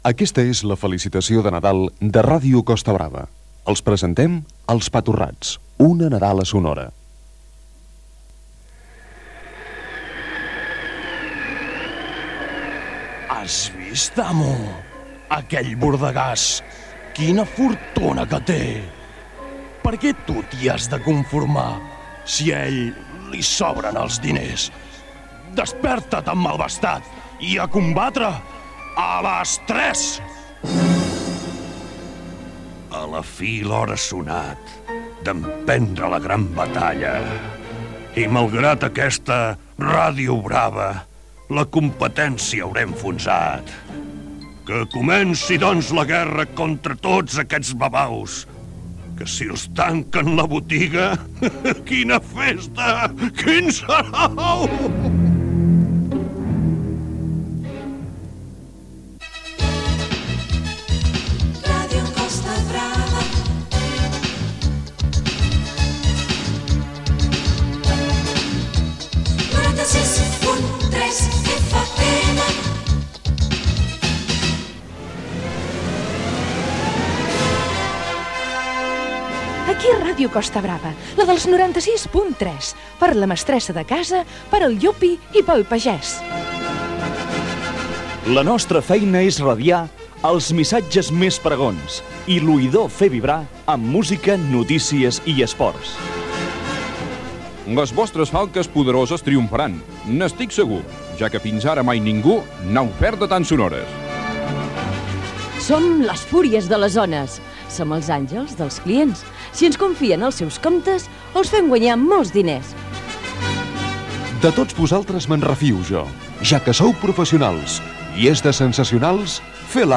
Felicitació de Nadal de l'emissora "Els pa torrats". Indicatiu de l'emissora, versos sobre l'emissora
Entreteniment
FM